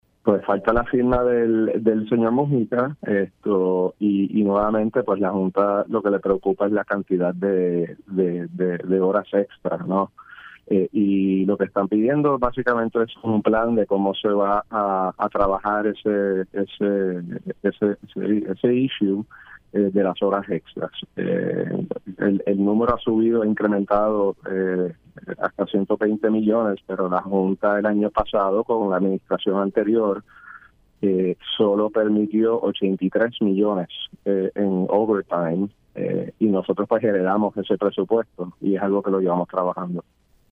El secretario de Seguridad Pública, Arthur Garffer indicó en Pega’os en la Mañana que solamente falta la firma del director ejecutivo de la Junta de Supervisión Fiscal (JSF), Robert Mujica para poder aprobar los pagos de horas extra a los oficiales del Negociado de la Policía de Puerto Rico (NPPR).